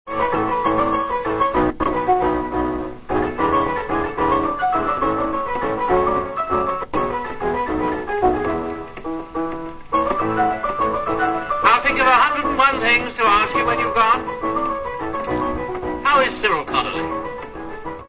Pianola